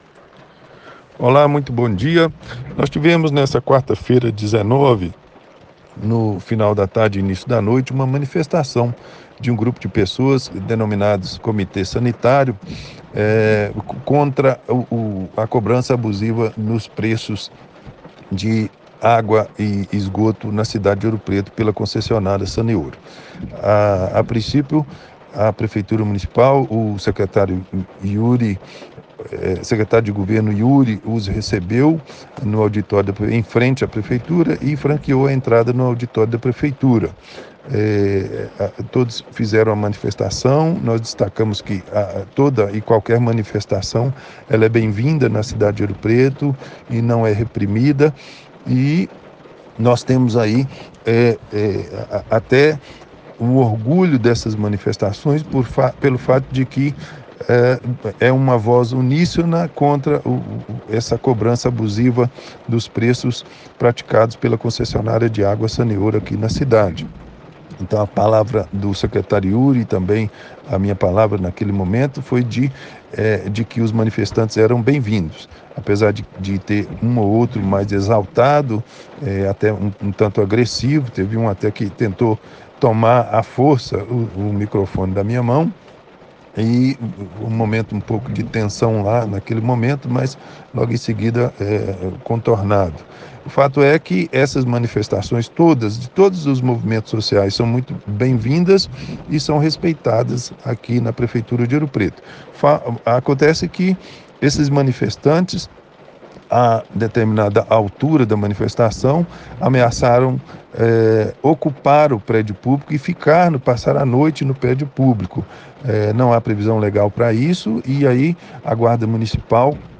Confira abaixo declaração do Secretário de Defesa Social, Juscelino Gonçalves, responsável pela Guarda Civil de Ouro Preto.